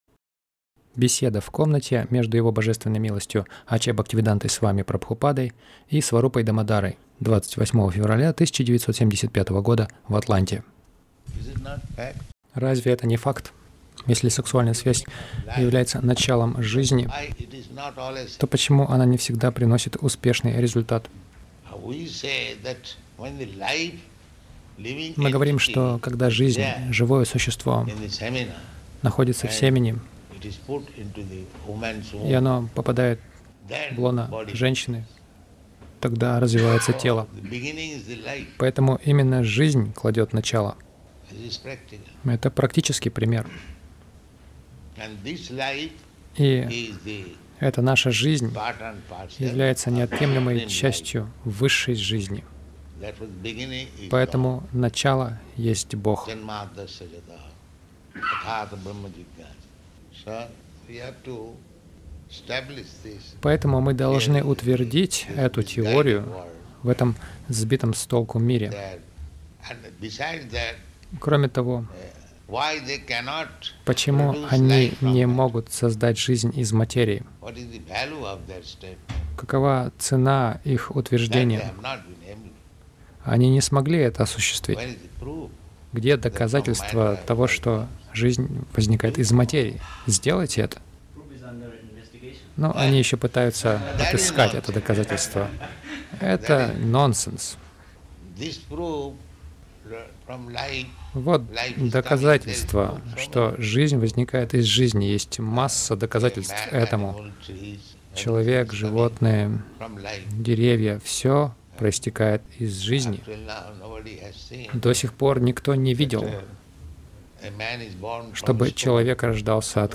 Беседа — Кто создал жизнь
Милость Прабхупады Аудиолекции и книги 28.02.1975 Беседы | Атланта Беседа — Кто создал жизнь Загрузка...